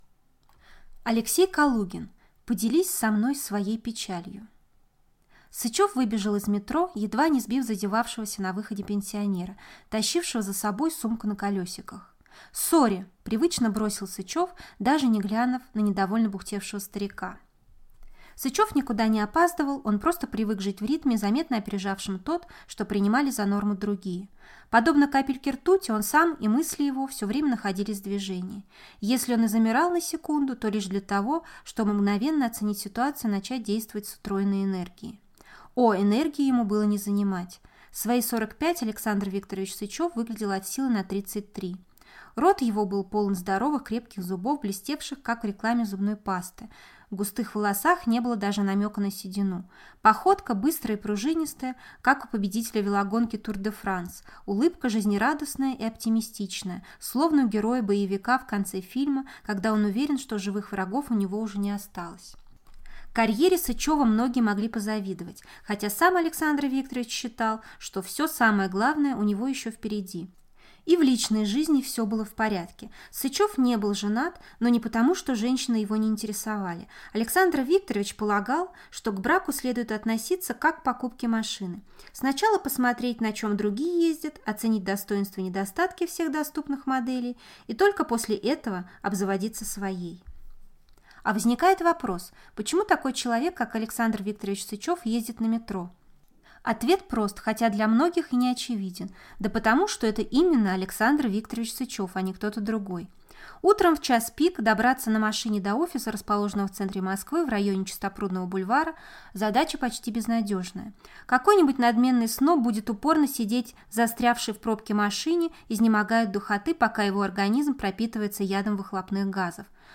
Аудиокнига Поделись со мной своей печалью | Библиотека аудиокниг
Прослушать и бесплатно скачать фрагмент аудиокниги